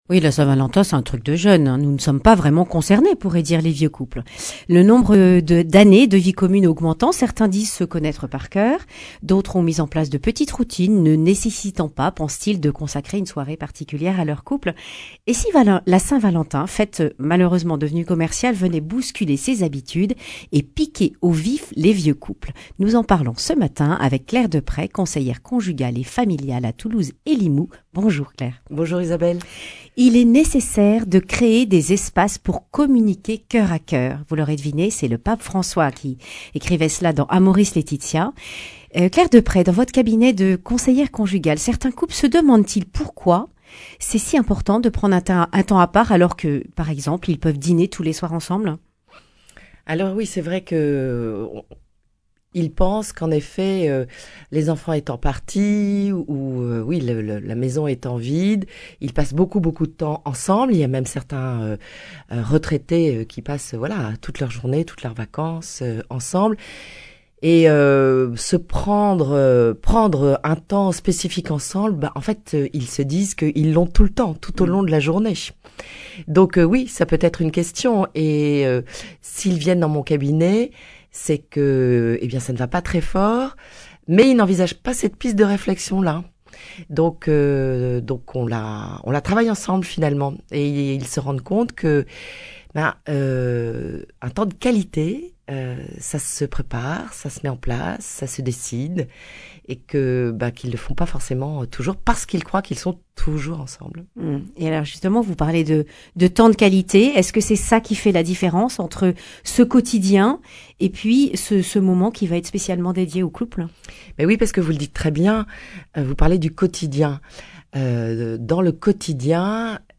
Accueil \ Emissions \ Information \ Régionale \ Le grand entretien \ La saint Valentin, réservée aux jeunes couples ?